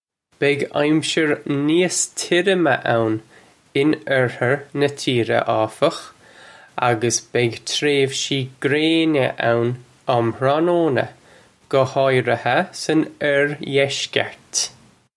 Pronunciation for how to say
Beg amsher nee-uss tirrimuh own in irrher nuh cheera, awfukh, uggus beg trayv-shee grayna own umm hra-noe-na, guh hawriha sun irr-yesh-kurt.